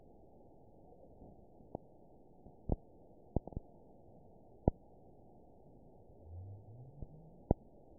event 922769 date 03/29/25 time 17:42:29 GMT (2 months, 2 weeks ago) score 6.62 location TSS-AB01 detected by nrw target species NRW annotations +NRW Spectrogram: Frequency (kHz) vs. Time (s) audio not available .wav